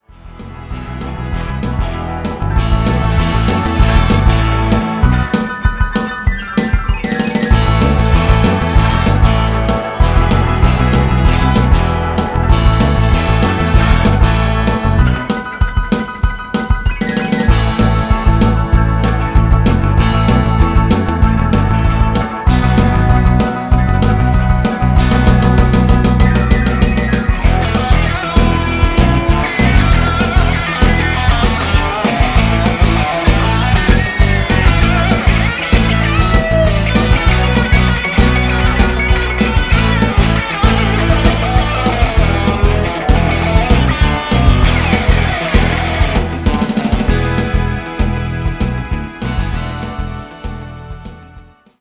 Live Zénith 2003